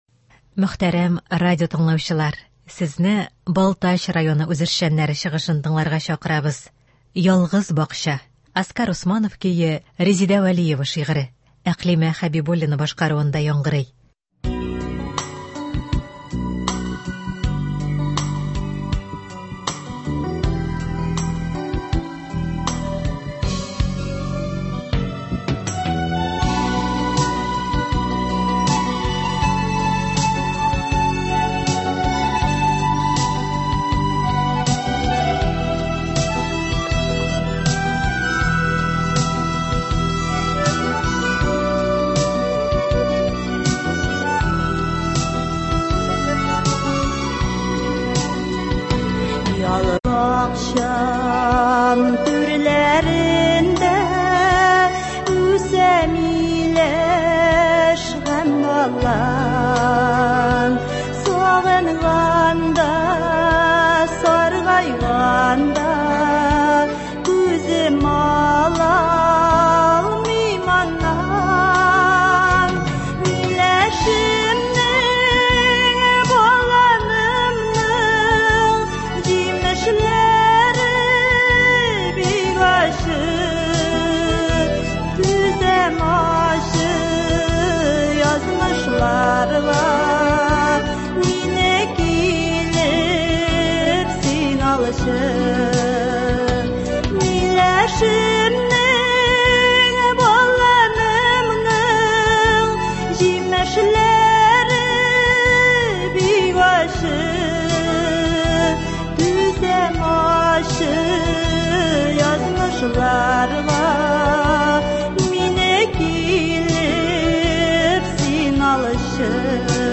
Үзешчән башкаручылар чыгышы.
Концерт (09.09.24)